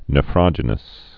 (nə-frŏjə-nəs) or neph·ro·gen·ic (nĕfrə-jĕnĭk)